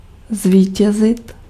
Ääntäminen
IPA: [ɑ̃.pɔʁ.te]